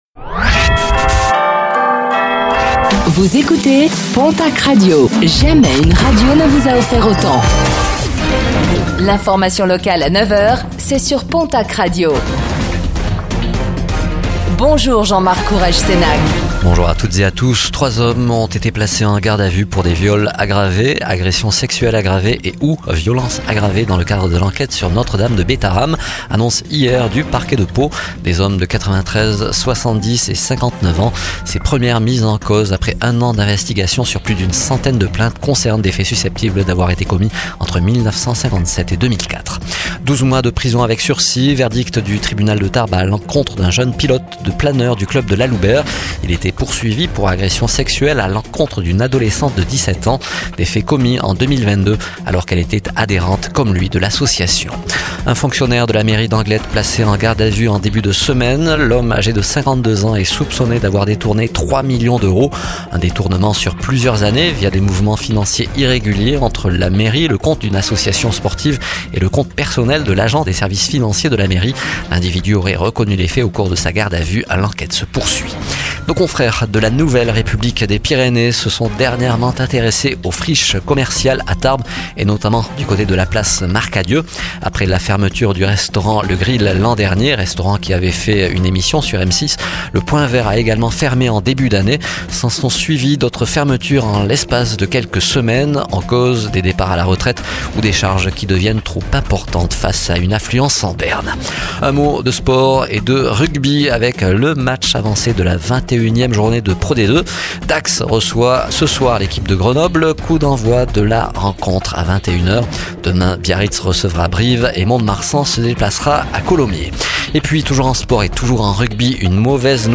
09:05 Écouter le podcast Télécharger le podcast Réécoutez le flash d'information locale de ce jeudi 20 février 2025